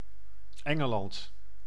Ääntäminen
Vaihtoehtoiset kirjoitusmuodot (vanhahtava) Ingland Ääntäminen UK US UK : IPA : /ˈɪŋɡlənd/ US : IPA : /ˈɪŋɡlənd/ GenAm: IPA : /ˈɪŋɡlɪ̈nd/ Haettu sana löytyi näillä lähdekielillä: englanti Käännös Ääninäyte Erisnimet 1.